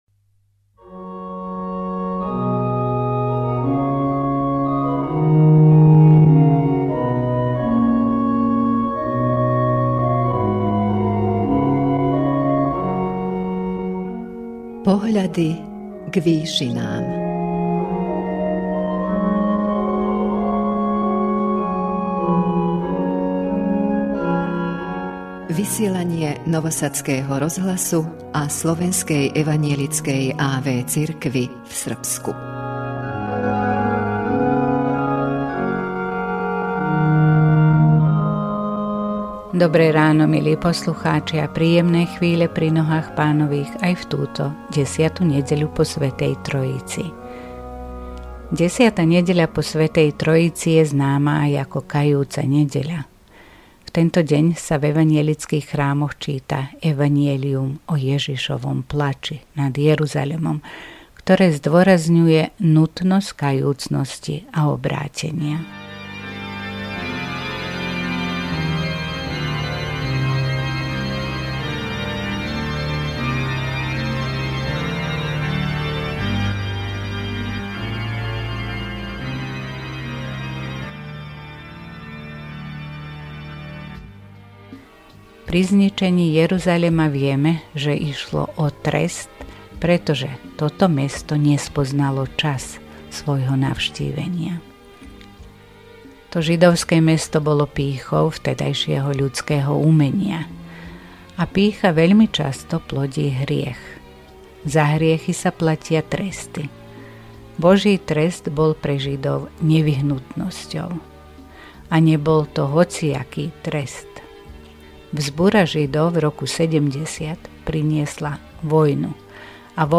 V duchovnej relácii Pohľady k výšinám Rádia Nový Sad a Slovenskej evanjelickej a.v. cirkvi v Srbsku v túto 10. nedeľu po Svätej Trojici duchovnú úvahu